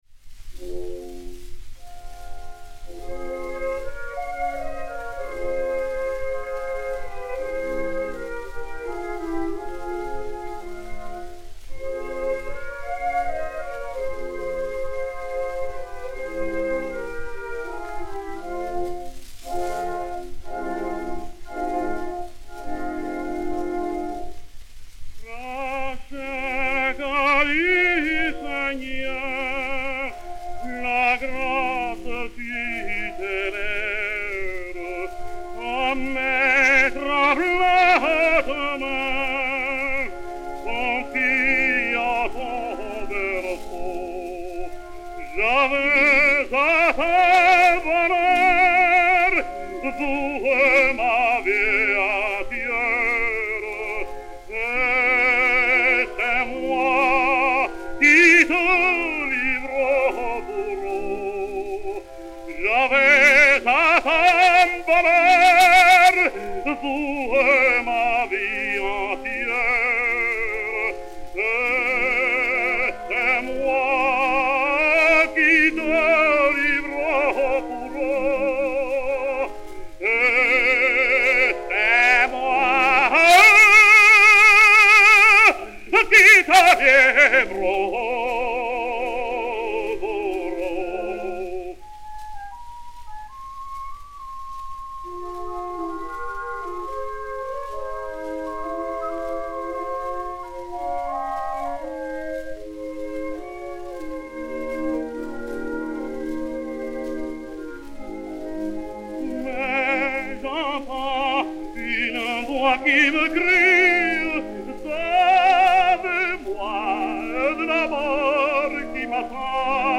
Enrico Caruso (Eléazar) et Orchestre dir Josef Pasternack